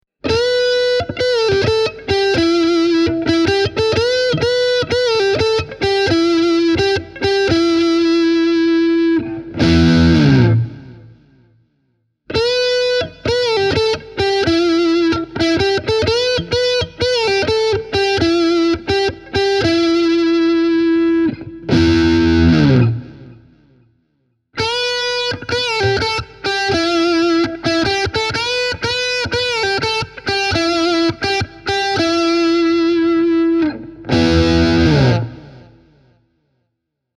Lähtötaso on hyvin maltillinen ja perussoundi on mukavan avoin. Kaulamikki on lämmin, mutta erotteleva, ja valitsimen keskiasennossa soundi on ilmava. Tallahumbucker taas soi hyvin tuoreella äänellä, mutta ei ”marise” häiritsevästi keskialueella.